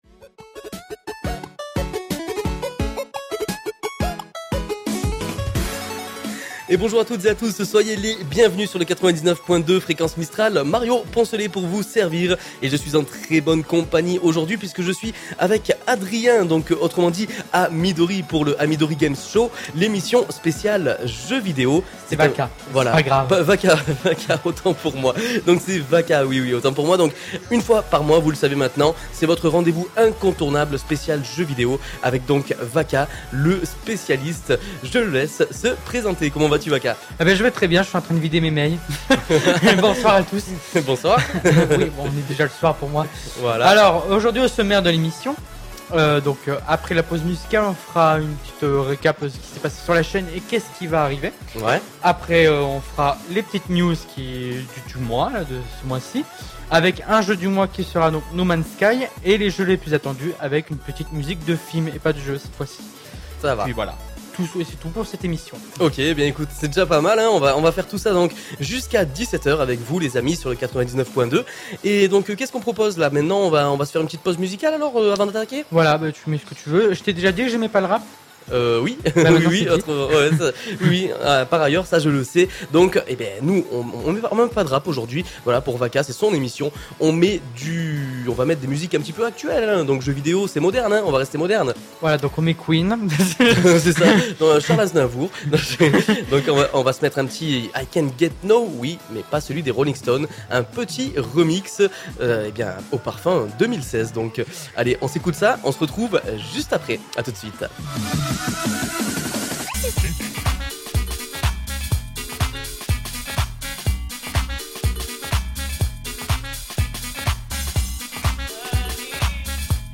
Nouveau sur Fréquence Mistral ! Seconde émission mensuelle « AMIDORI GAMES SHOW » sur l’univers des jeux vidéos !